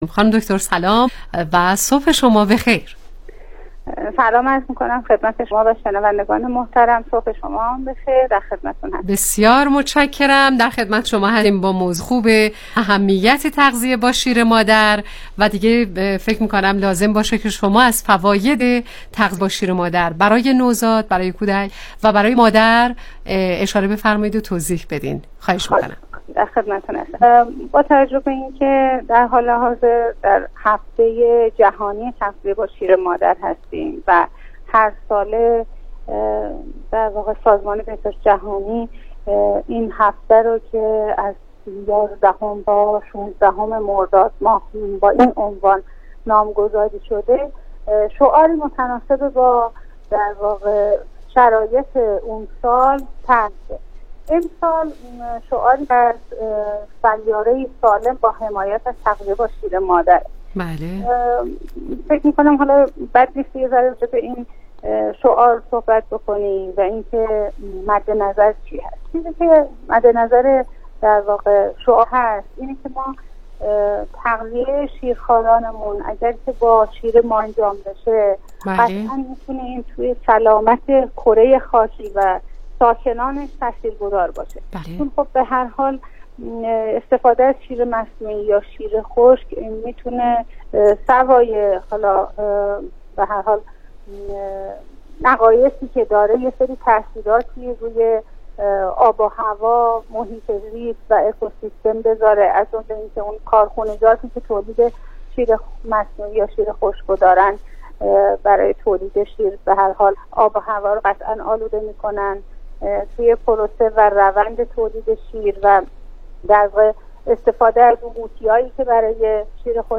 گفتگوی تلفنی برنامه کانون مهر